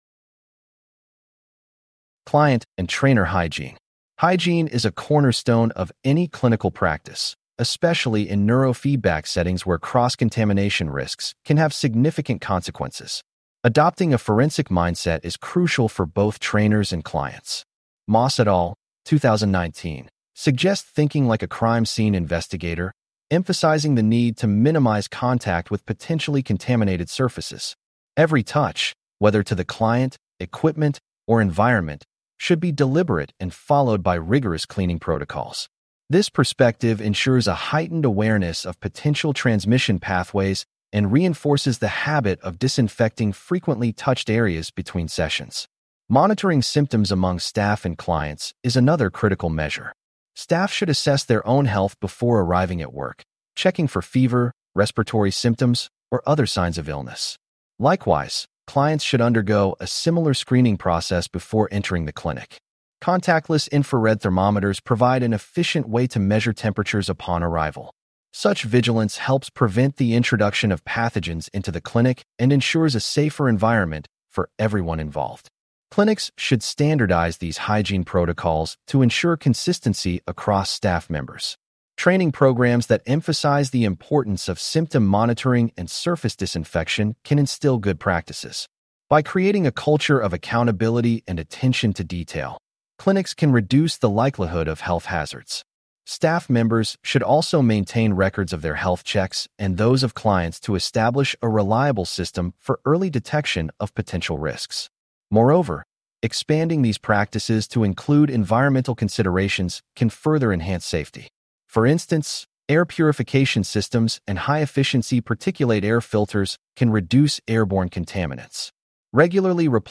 This unit reviews Client and Trainer Hygiene, Screening, Hand Cleaning, Masks, Social Distance, Equipment Sterilization, Cross-Contamination, and Best Practices. Please click on the podcast icon below to hear a full-length lecture.